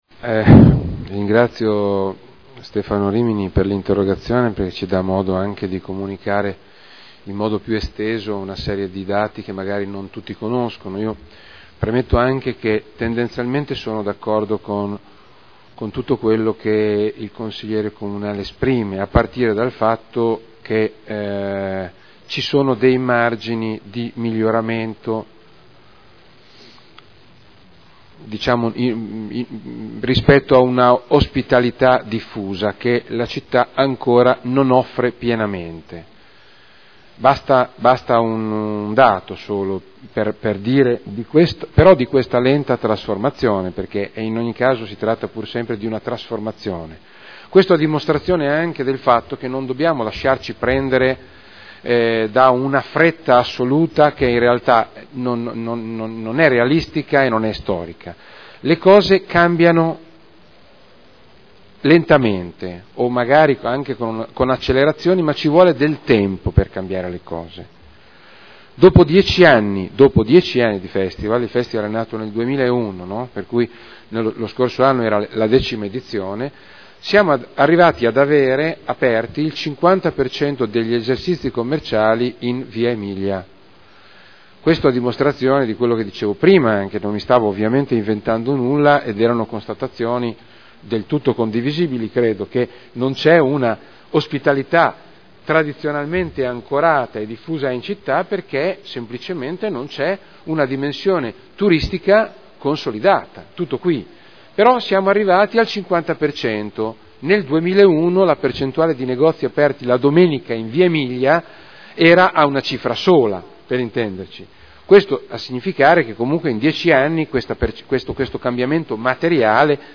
Roberto Alperoli — Sito Audio Consiglio Comunale